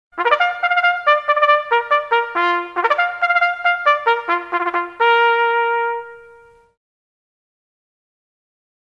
Звук горна пионеров